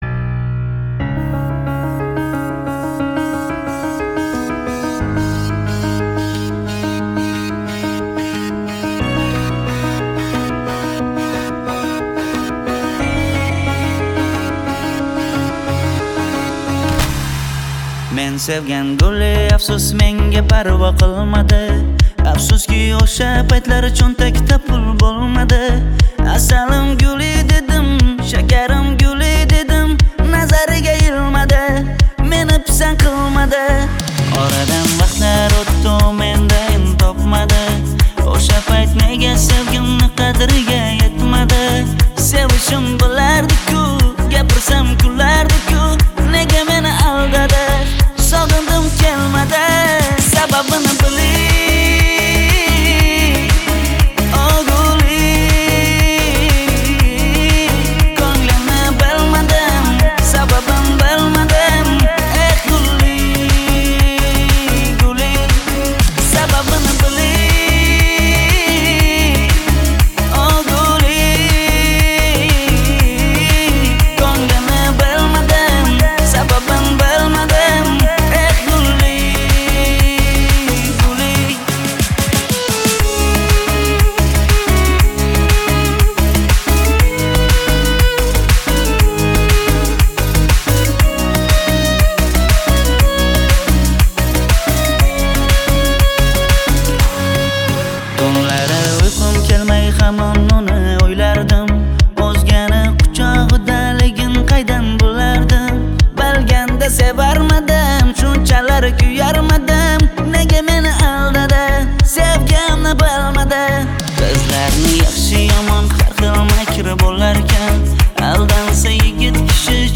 Трек размещён в разделе Узбекская музыка / Поп / 2022.